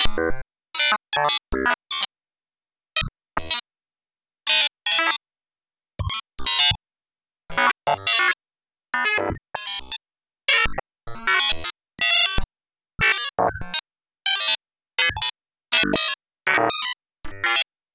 Track 16 - Glitch 02.wav